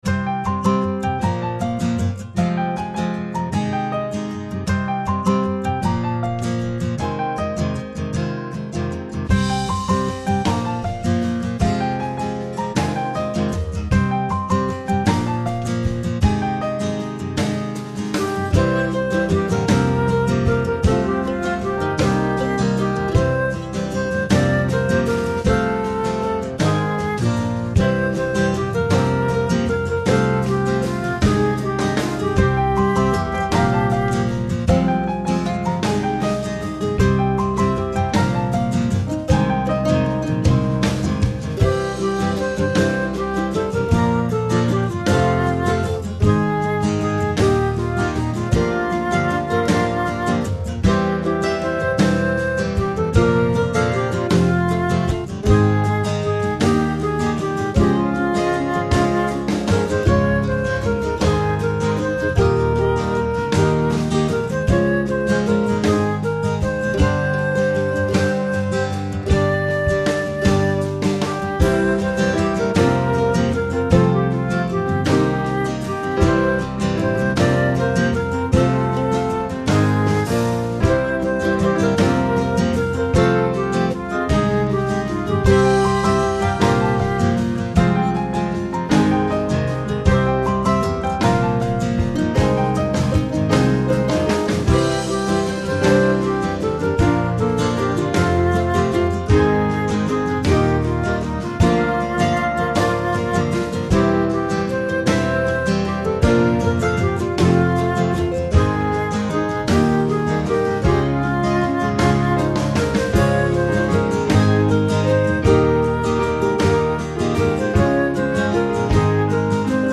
advent song